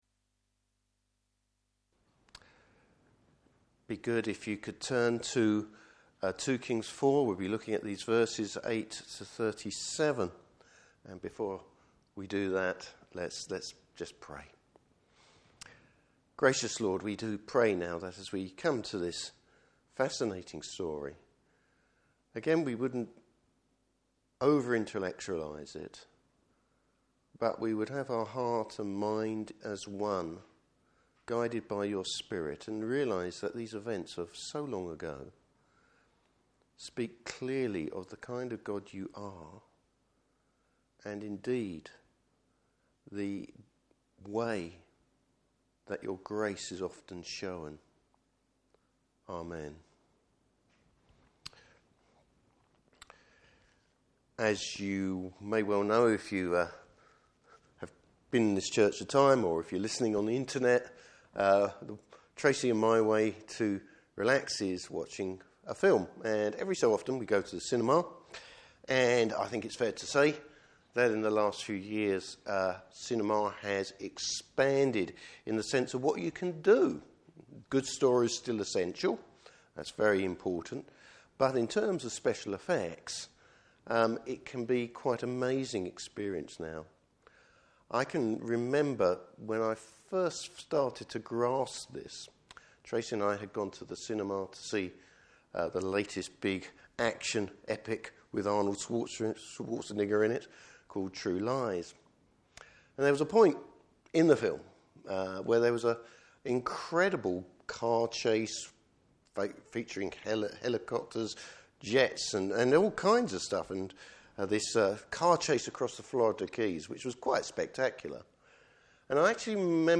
Service Type: Evening Service Bible Text: 2 Kings 4:8-37.